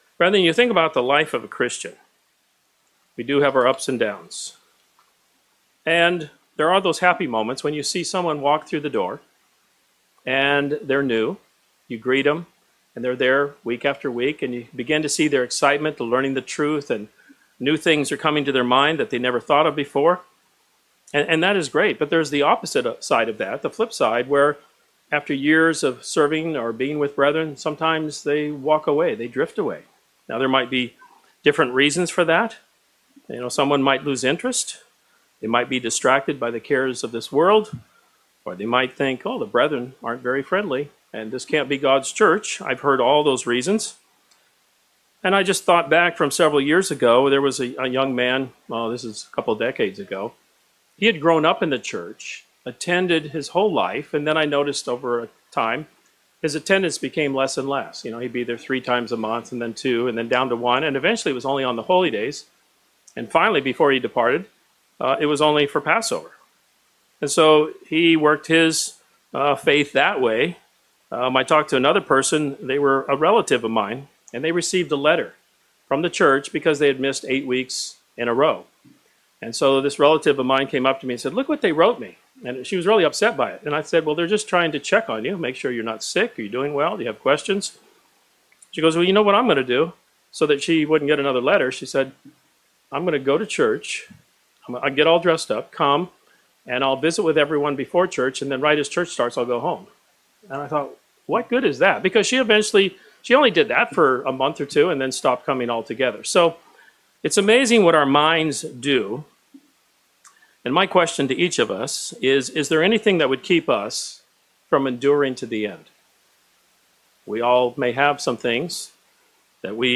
This sermon discusses three basic steps that are necessary for disciples in preparing to be the bride of Christ.